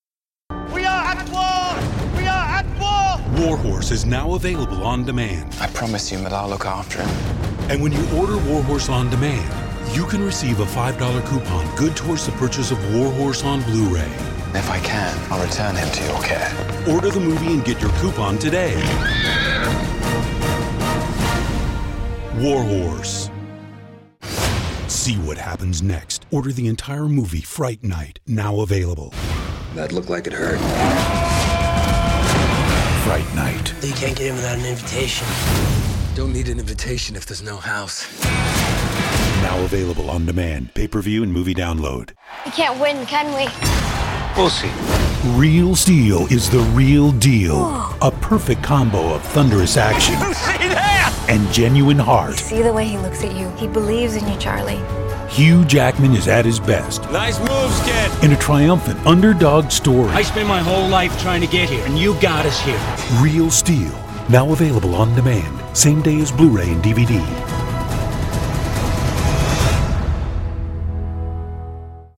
Baritone, warm, comforting, powerful, authoritive, sincere, authentic, fun, relaxed, conversational.
mid-atlantic
middle west
Sprechprobe: Sonstiges (Muttersprache):